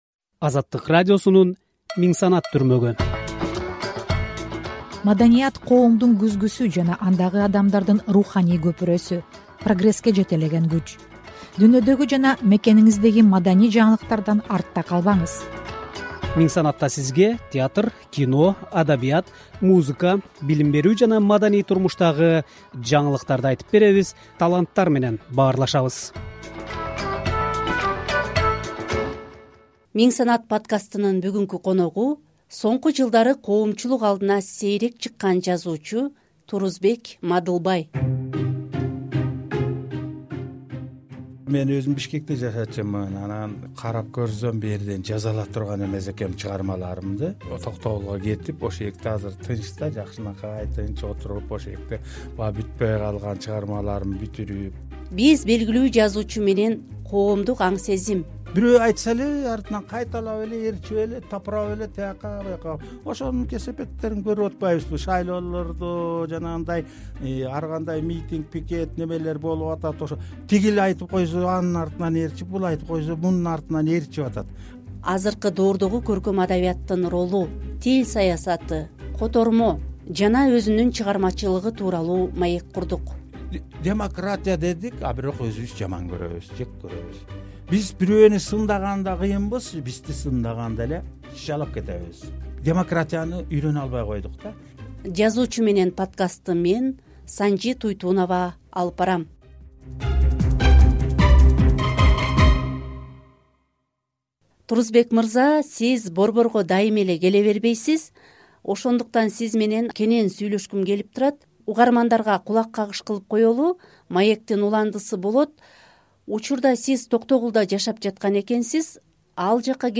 “Миңсанаттын" коногу кыйла жылдан бери коомчулук алдына чыкпай калган жазуучу Турусбек Мадылбай. Биз белгилүү жазуучу жана котормочу менен коомдук аң-сезим, азыркы доордогу көркөм адабияттын ролу тил саясаты, котормо жана өзүнүн чыгармачылыгы тууралуу маектин биринчи бөлүгүн сунуштайбыз.